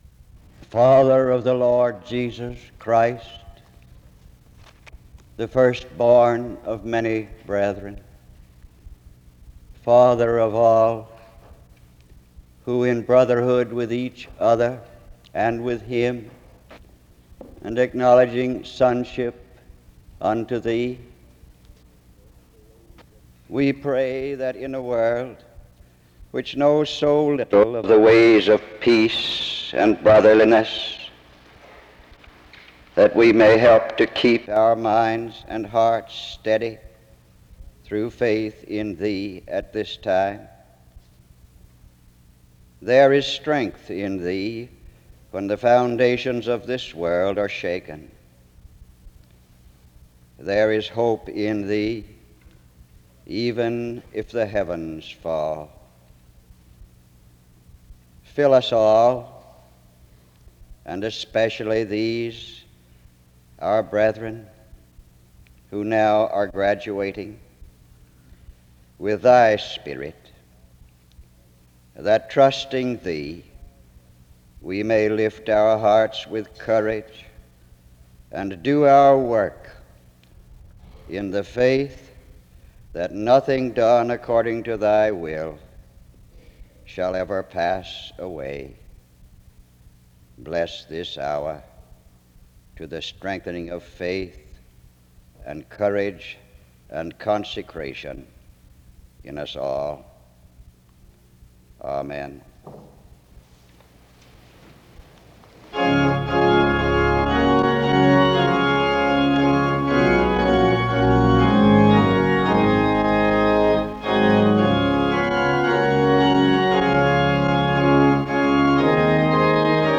This unique service was the 10th spring graduation commencement service since the creation of the Seminary in 1951.
Music plays from 1:45-4:28. Isaiah 52:1-10 is read from 4:46-7:38. A prayer is offered from 7:39-9:53.
Music plays from 14:09-17:56.